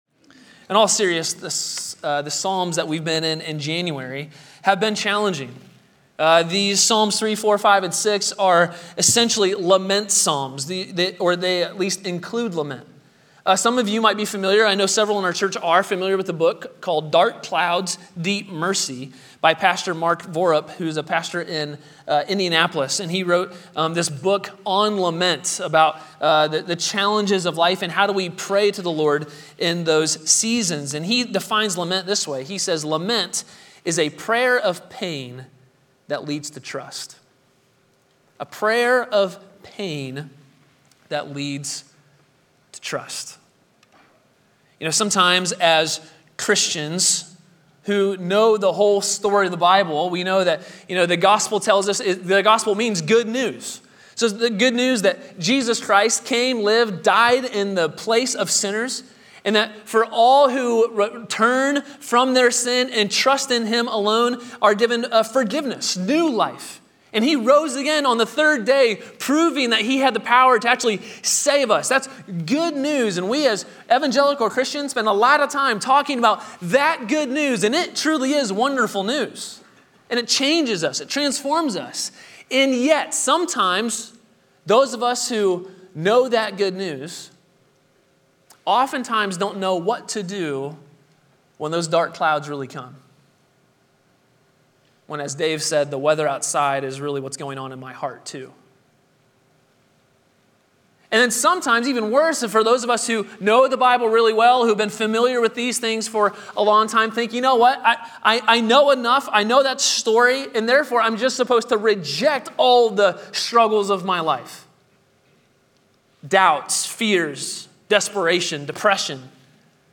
A sermon from the series "The Book of Psalms."